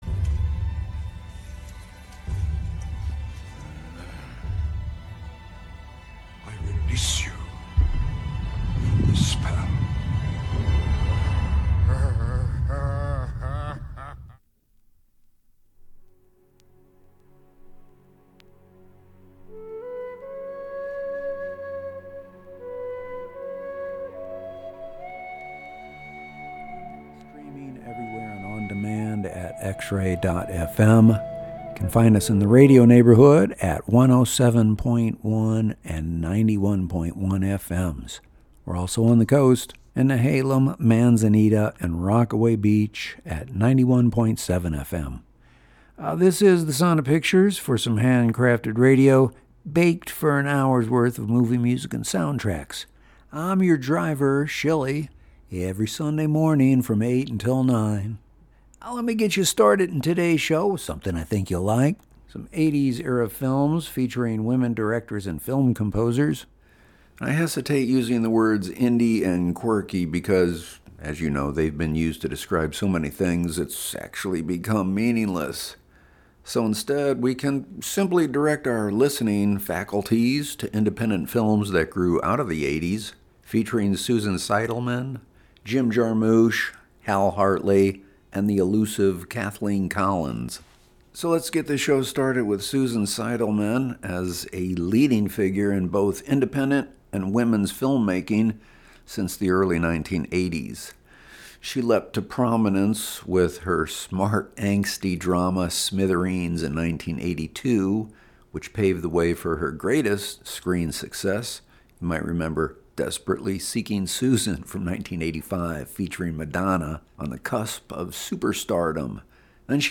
movie music show